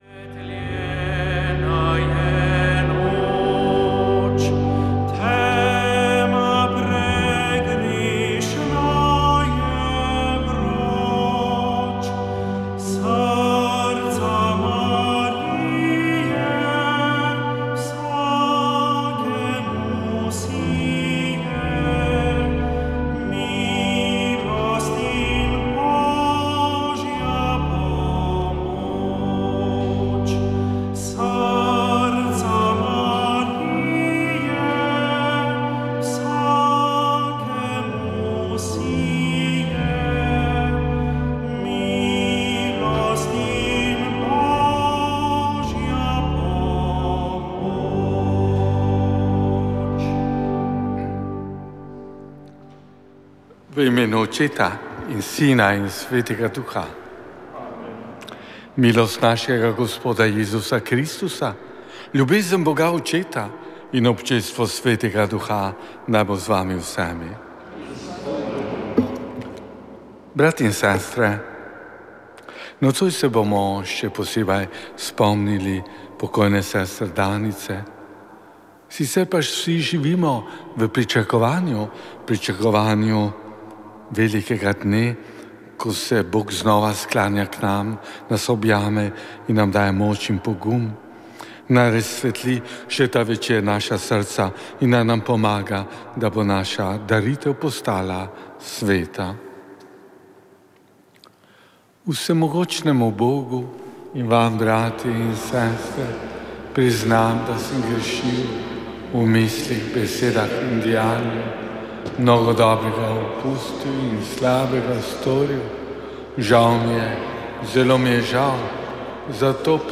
Sveta maša
Sv. maša iz cerkve sv. Marka na Markovcu v Kopru 4. 1.